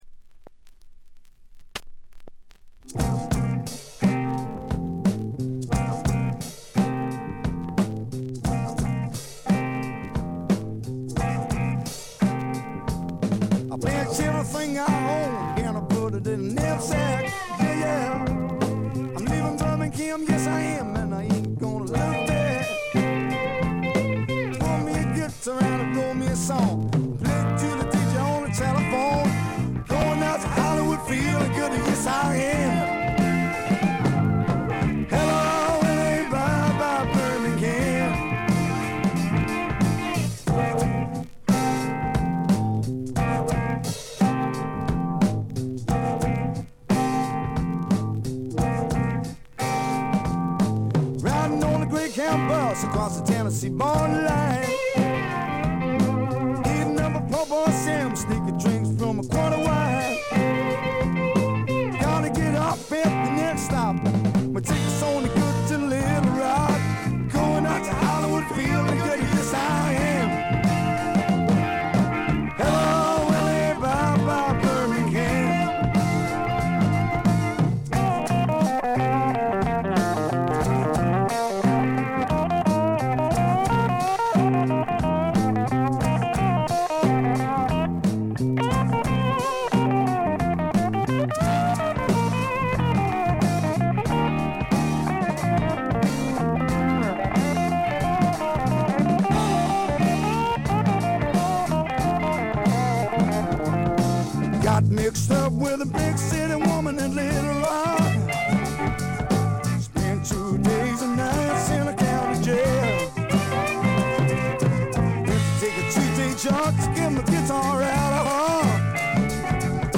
他はチリプチや散発的なプツ音は出るもののまずまず。
へヴィーな英国流ブルースロックをやらせたら天下一品。
試聴曲は現品からの取り込み音源です。